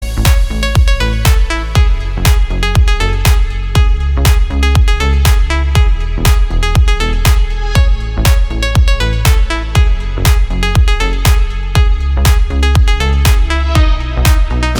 • Качество: 320, Stereo
мелодичные
веселые
без слов
Стиль: deep house